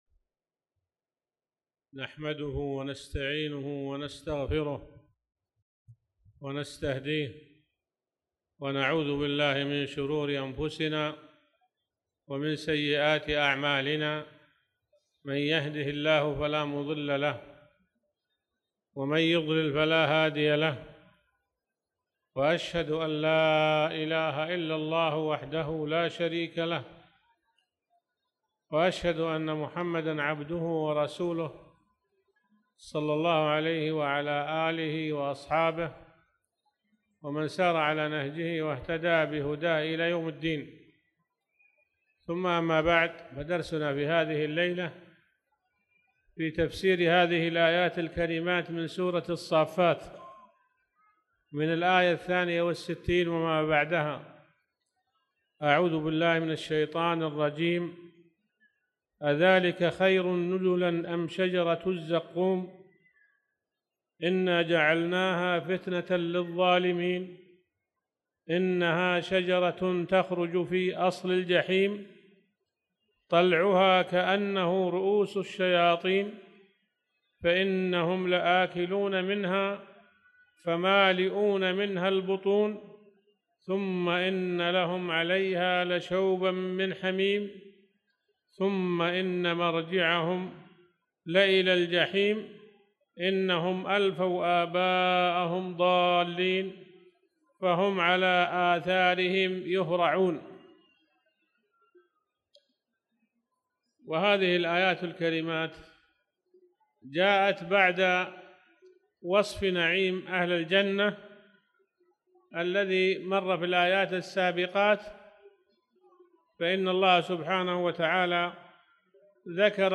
تاريخ النشر ١٤ شوال ١٤٣٧ المكان: المسجد الحرام الشيخ